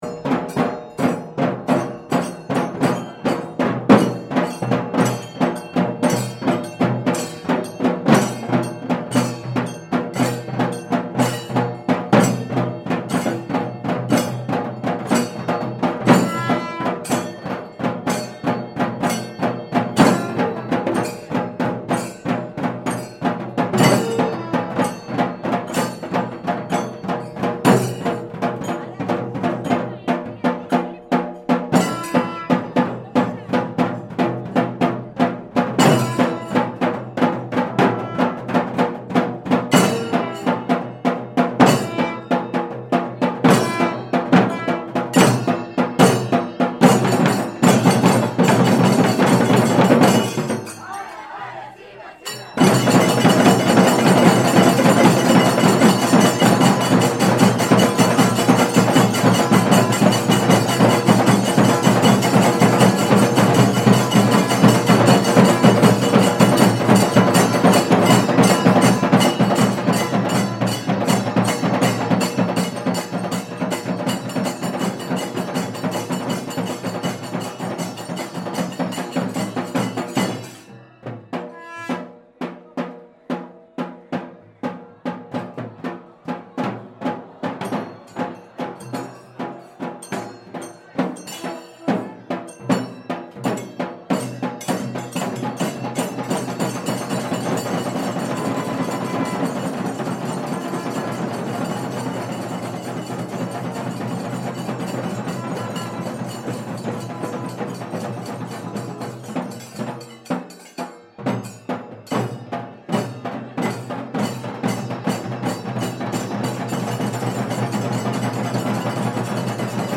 People are crowding into the temple, and you can feel the Tamil Nadu temple ambience.